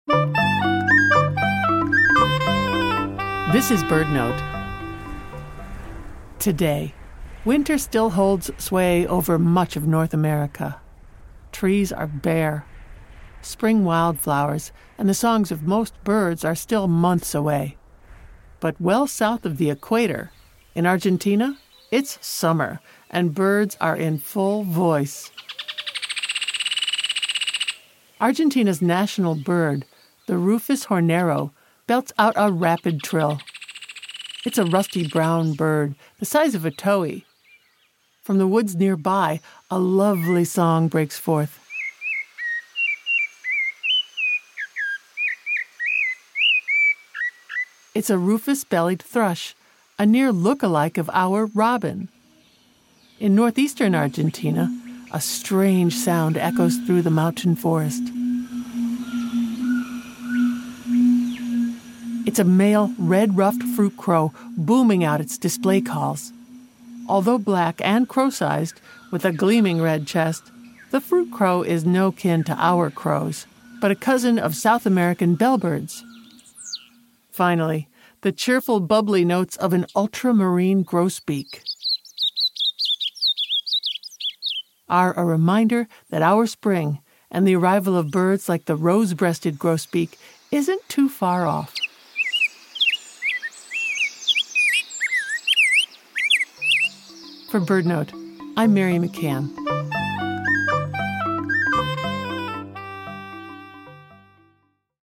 But in Argentina, it’s summer, and birds are in full voice. Argentina’s national bird, the Rufous Hornero, belts out a rapid trill while the Rufous-bellied Thrush sings its lovely song.